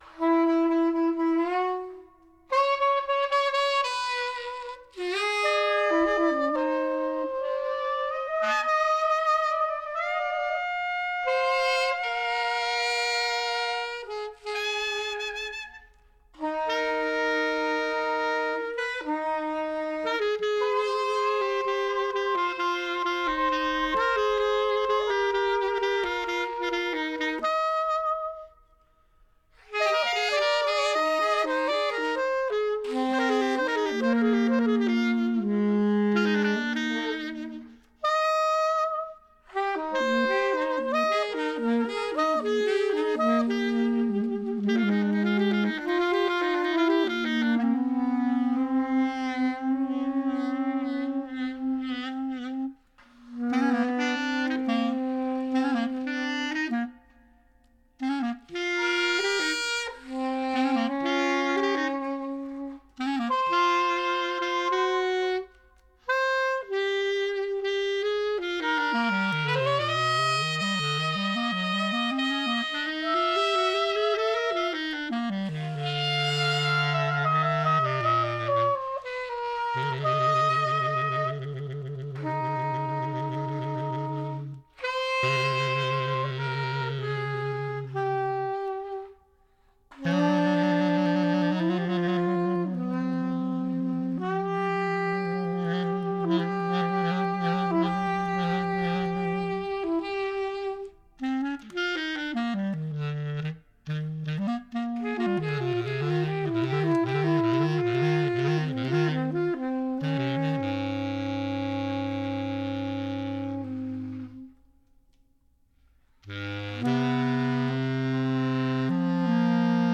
Recorded live at the Maid’s Room, NYC March 27, 2009
alto clarinet, alto saxophone
soprano & alto saxophones
Stereo (Pro Tools)